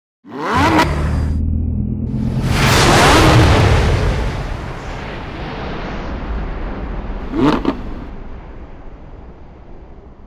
• Uniformidad del sonido
• Sonido Fino deportivo
sport-muffler.mp3